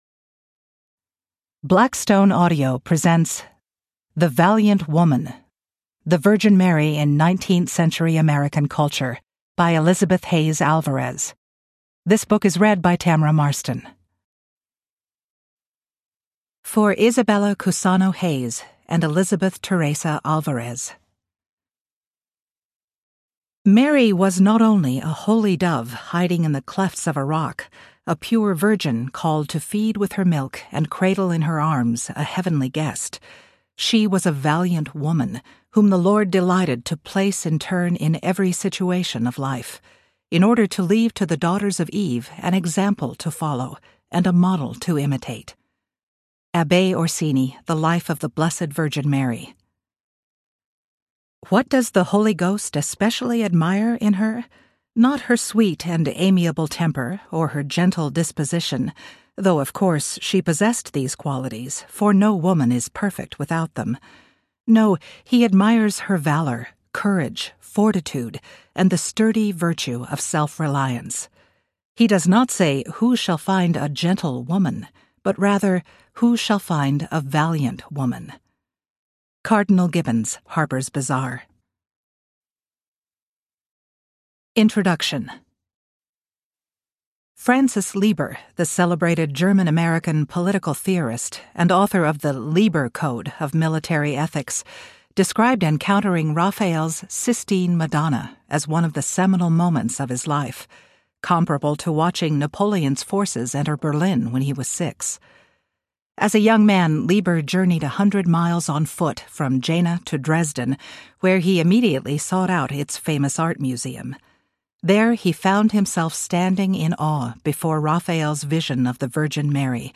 The Valiant Woman Audiobook
9.4 Hrs. – Unabridged